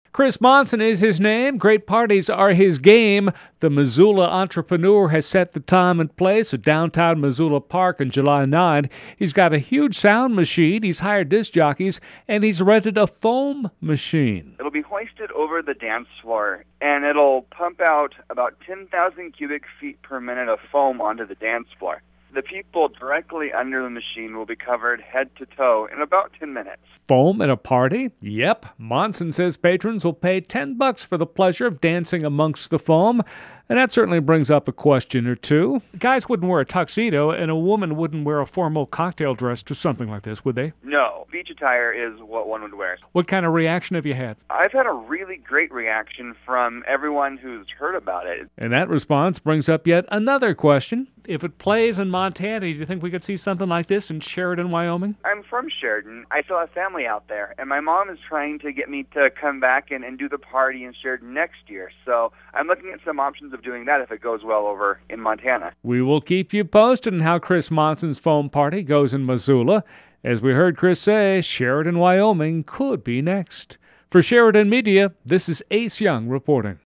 sheridanmediainterview1.mp3